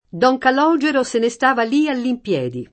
impiedi, all’ [all impL$di] locuz. avv. — es.: don Calogero se ne stava lì all’impiedi [